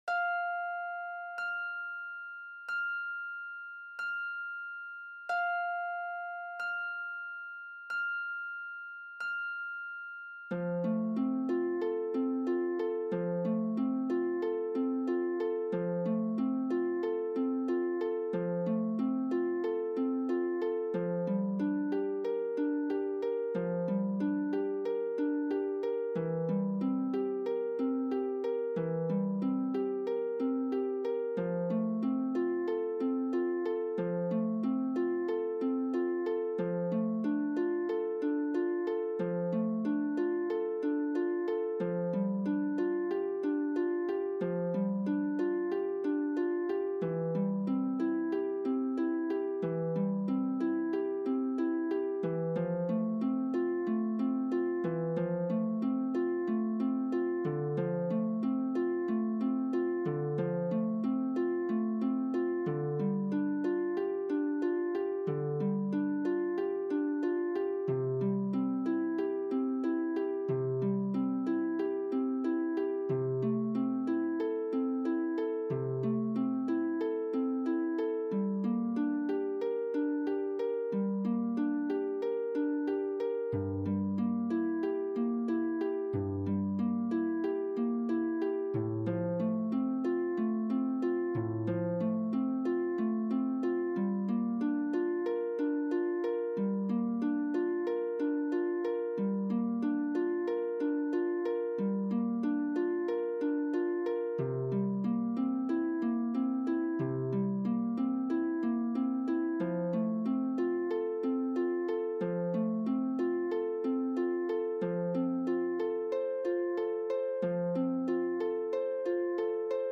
Zephyr Rehearsal Tracks
3.Ave_Maria_Harp_2_reh46.mp3